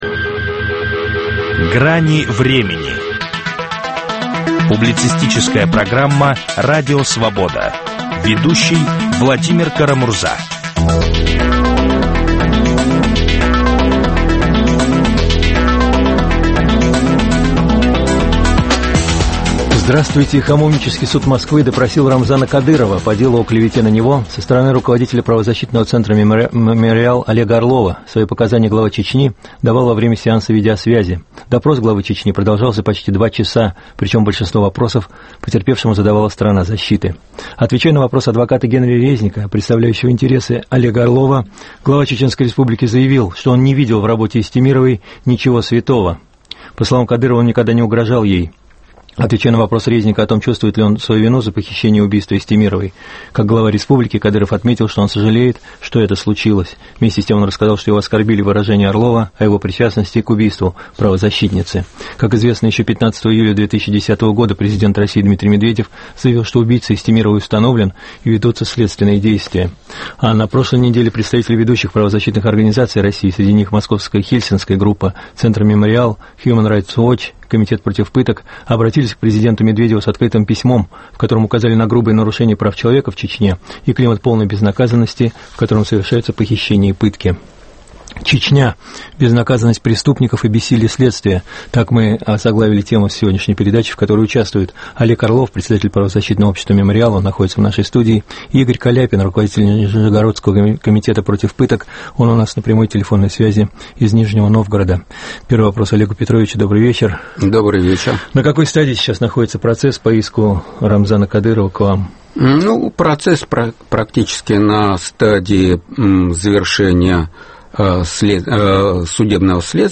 Чечня: безнаказанность преступников и бессилие следствия. В программе - Олег Орлов, председатель правозащитного общества "Мемориал" и Игорь Каляпин, председатель нижегородского Комитета против пыток.